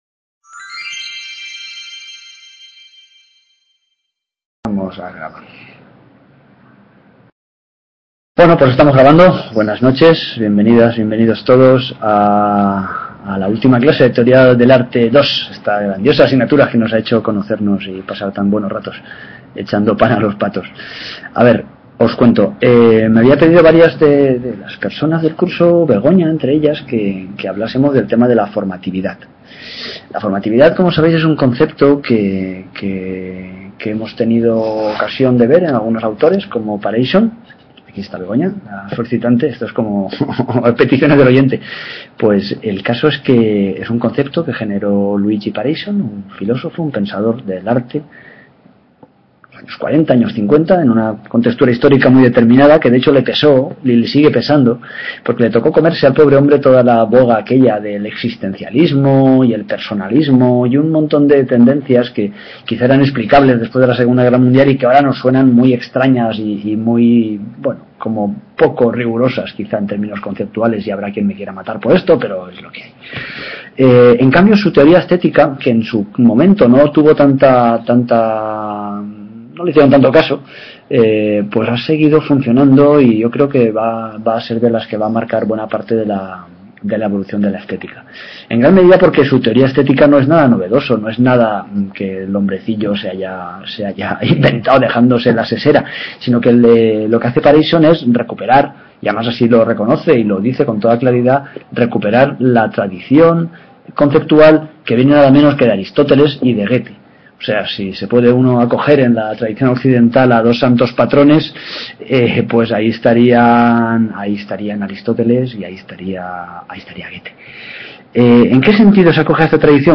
Teoria del arte II: Ultima clase sobre la Formatividad | Repositorio Digital